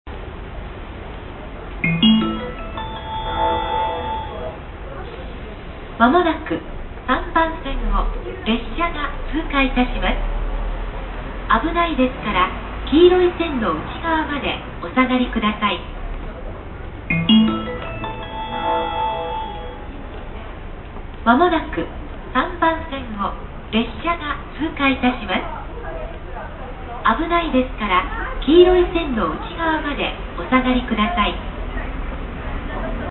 通過接近放送(列車) 通過放送です。